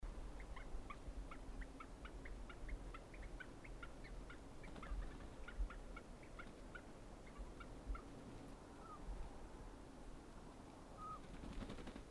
Ääniä, Kuukkeli
Kuukkelin lähikuvia ja vienoa jutustelua
Kuuntele kuinka kuukkeli juttelee minulle!